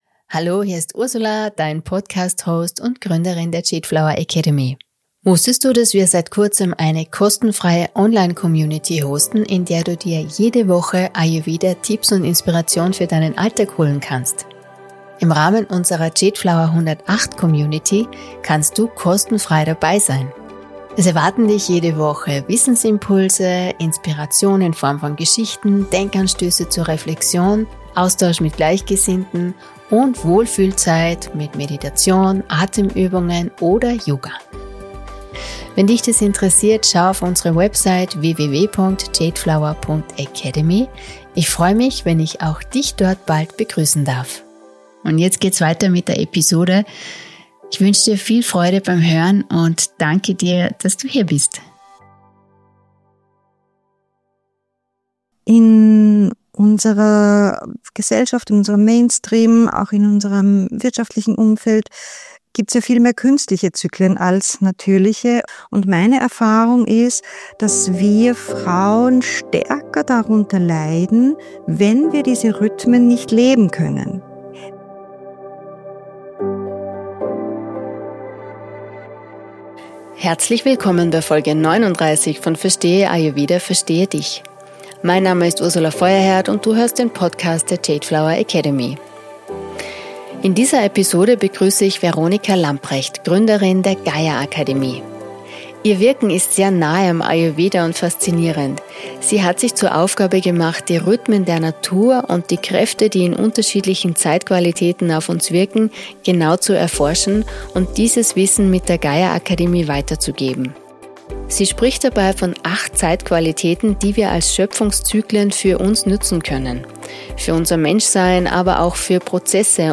Sie spricht dabei von 8 Zeitqualitäten, die wir als Schöpfungszyklen für uns nutzen können, für unser Mensch-Sein, aber auch für Prozesse und berufliche Projekte. Im Interview erfährst Du, welche Zeitqualitäten es gibt und welche Fragen Du Dir stellen kannst, um mit ihnen zu arbeiten.